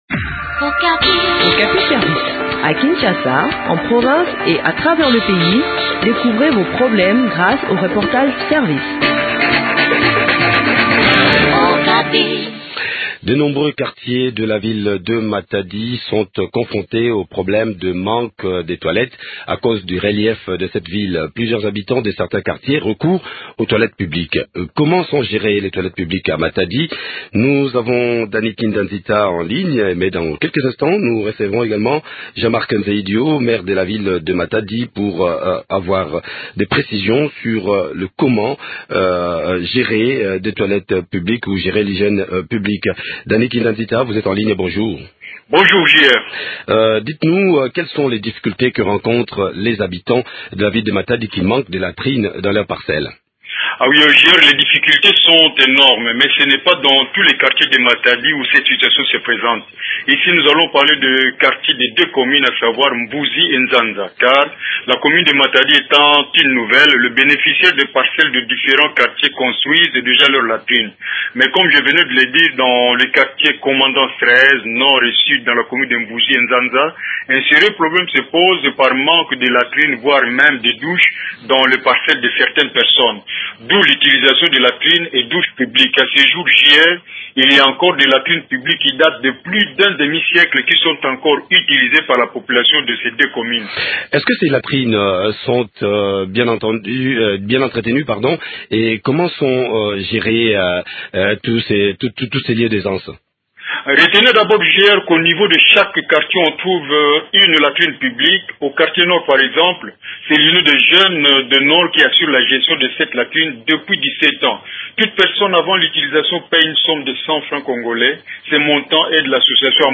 Jean Marc Nzeyidio, le maire de la ville de Matadi, en parle